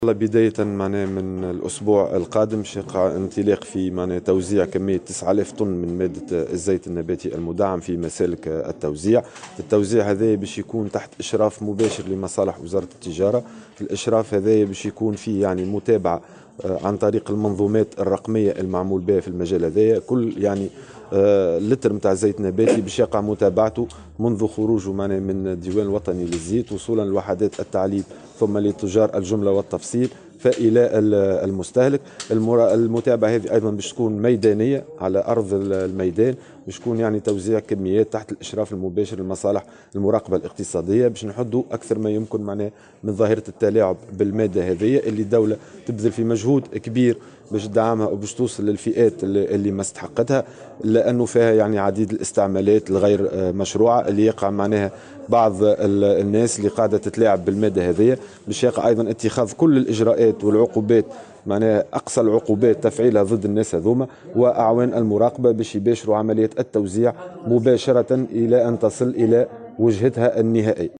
وأضاف خلال ندوة صحفية عقدتها وزارة التجارة، اليوم السبت، أن عمليات التوزيع ستتم تحت إشراف مباشر لمصالح المراقبة الاقتصادية للحد من ظاهرة التلاعب بهذه المادة المدعّمة، مؤكدا أيضا أنه سيتم فرض عقوبات ضدّ كل المخالفين.